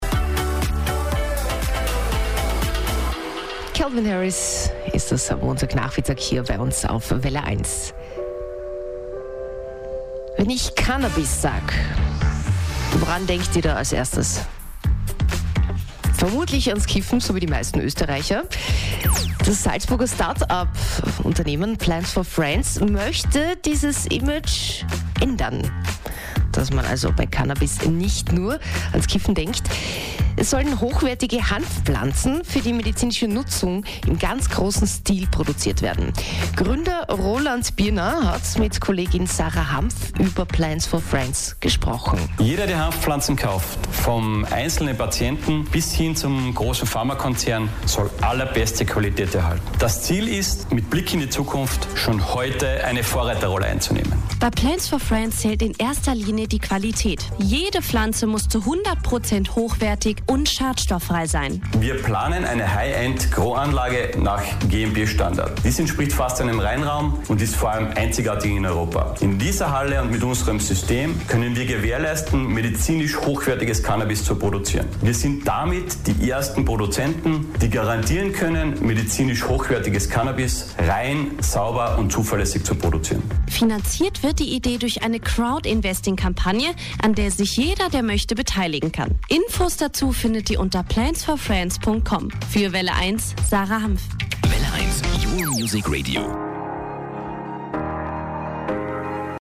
09_Radio-Mitschnitt-Welle_1.mp3